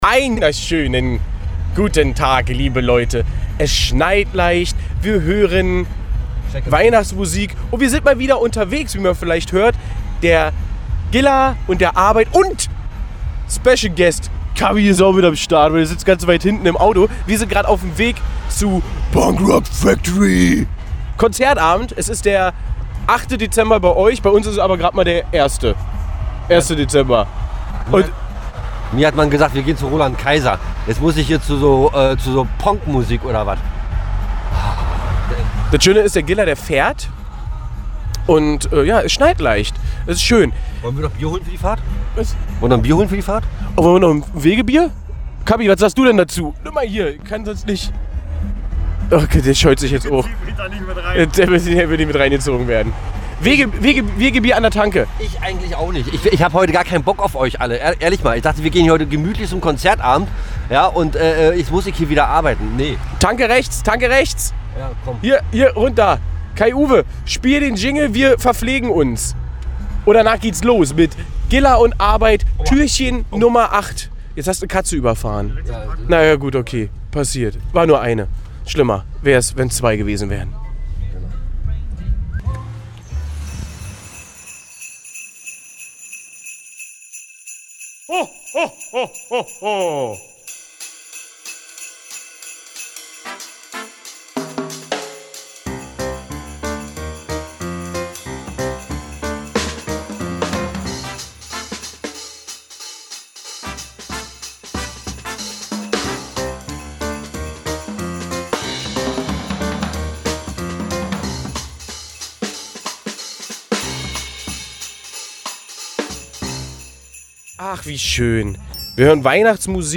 Was sie da erleben erfahrt ihr fast live hier!